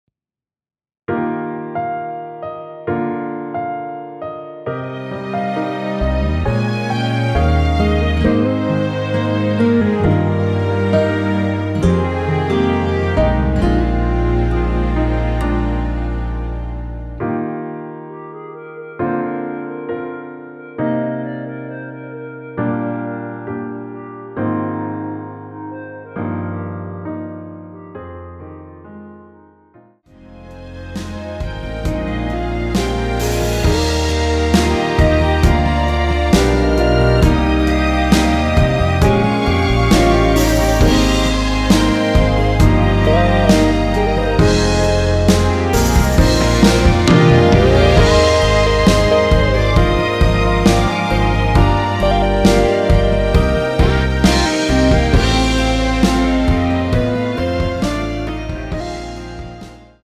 원키 멜로디 포함된 MR입니다.(미리듣기 확인)
Eb
앞부분30초, 뒷부분30초씩 편집해서 올려 드리고 있습니다.
중간에 음이 끈어지고 다시 나오는 이유는